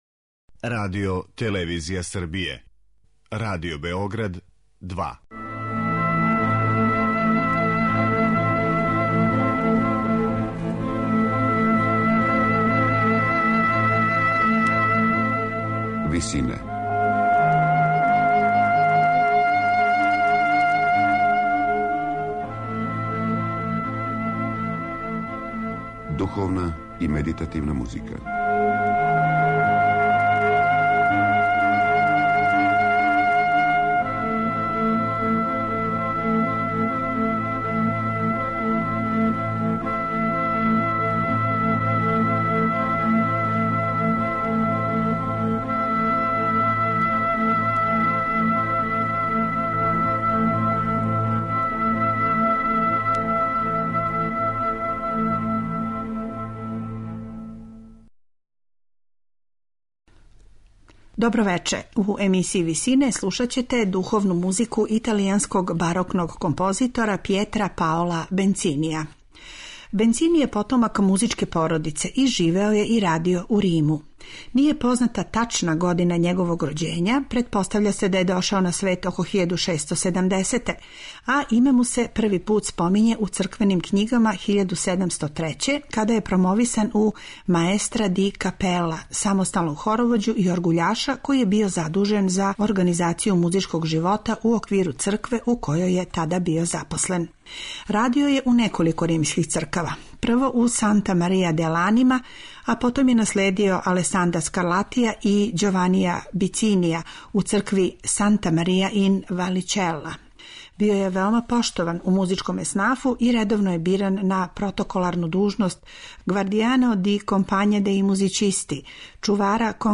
Емисија духовне и медитативне музике
У емисији Висине слушаћете духовну музику италијанског барокног композитора Пјетра Паола Бенцинија.